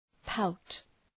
Προφορά
{paʋt}
pout.mp3